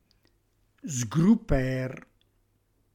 Qui di seguito il nostro repertorio delle parole “reggiane” proprie del nostro dialetto, sia per vocabolo che per significato ad esso attribuito, corredate della traccia audio con la dizione dialettale corretta.